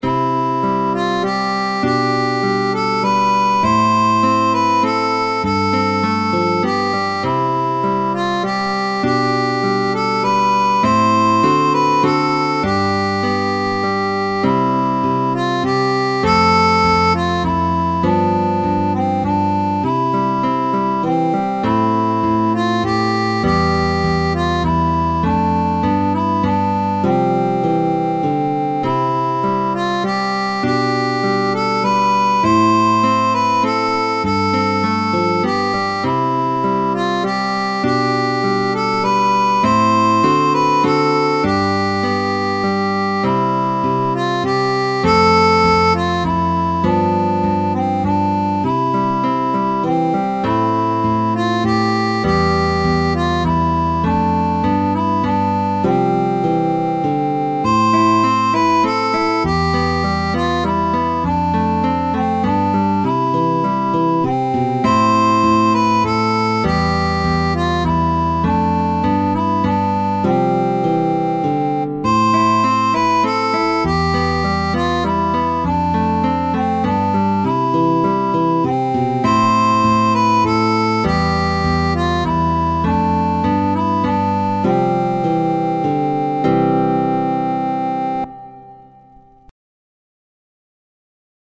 La filino de l' merkatisto, popola fama kanto hispana,
ludita de mi mem.